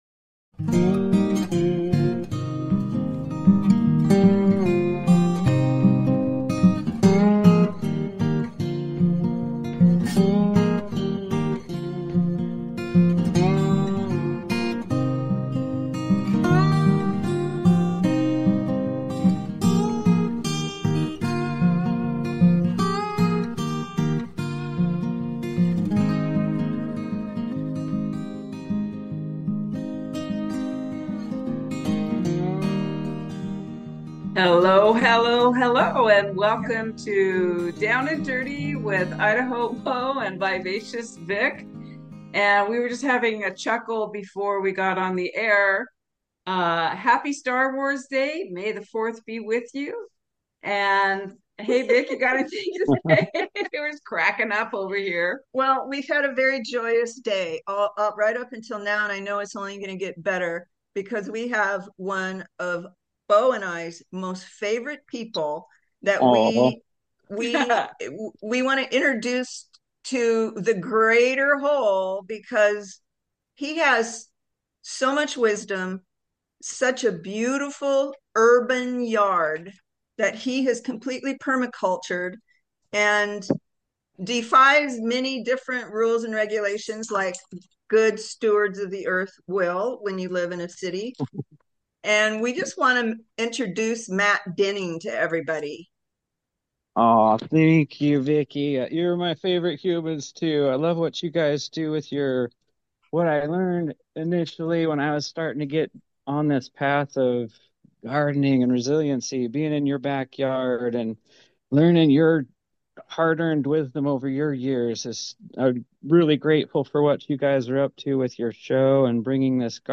The Spirit of Gardening: Our 1st Live Show. We'll take calls & answer your gardening questions